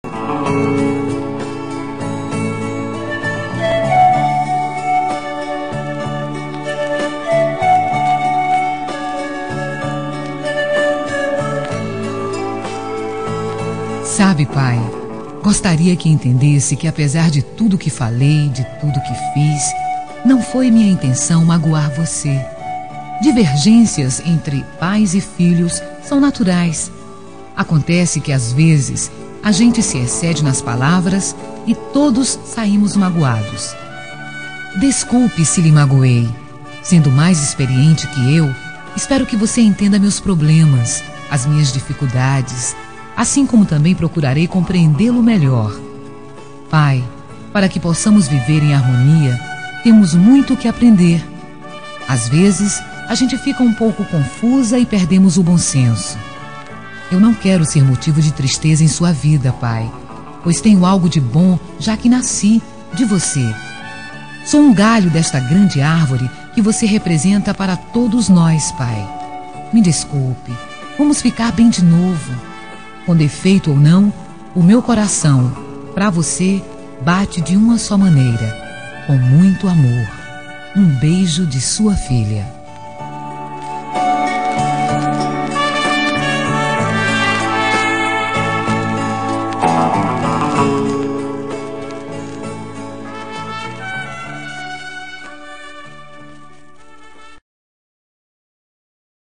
Reconciliação Familiar – Voz Feminina – Cód: 088724 – Pai